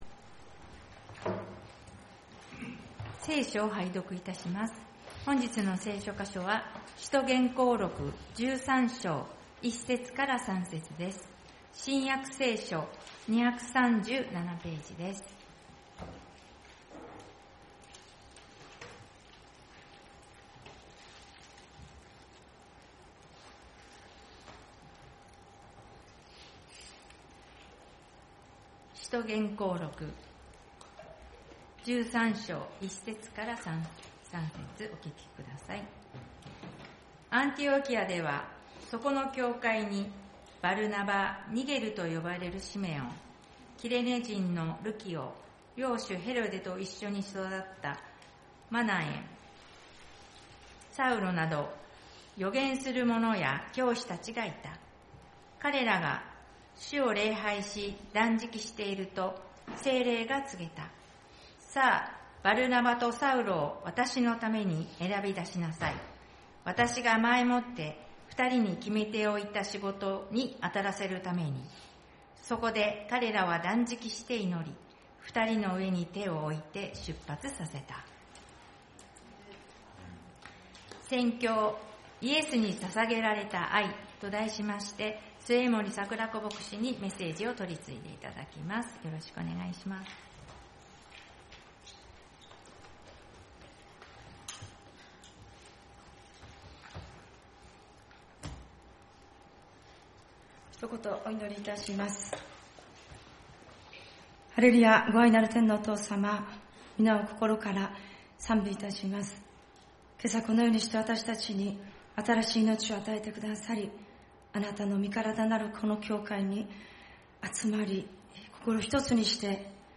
聖日礼拝「イエスに献げられた愛